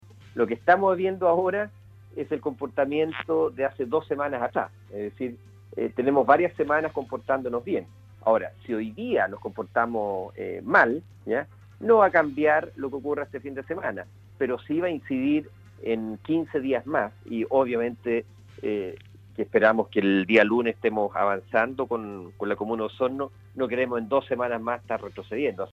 En conversación con Radio Sago el Seremi de Salud, entregó una señal de confianza a la comunidad de Osorno, dando cuenta de una reunión sostenida con el Ministro de Salud Enrique Paris, instancia donde se analizó la situación de la región de Los Lagos y de Osorno en particular. que ya cumple cuatro semanas de confinamiento En ese marco, la autoridad de salud en la región, indicó que de acuerdo a la tendencia de la comuna a la baja, continúa, por tanto se mostró confiado que el próximo lunes Osorno pueda salir de la cuarentena.